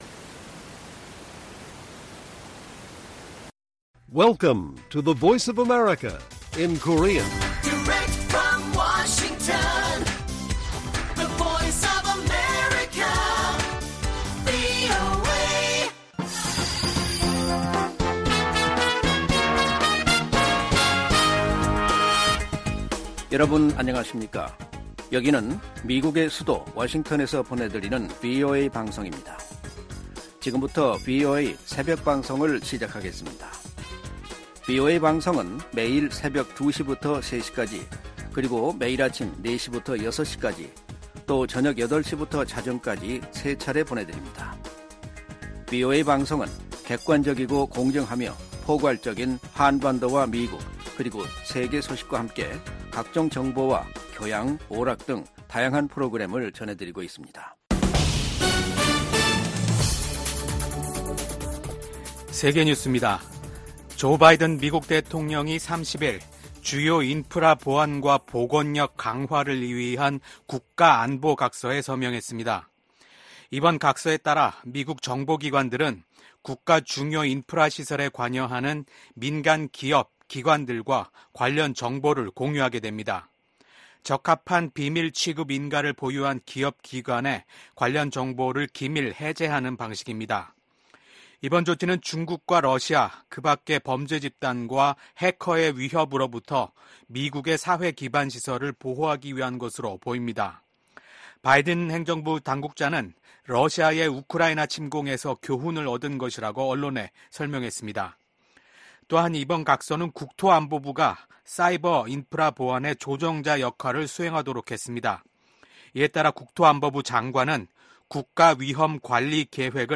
VOA 한국어 '출발 뉴스 쇼', 2024년 5월 2일 방송입니다.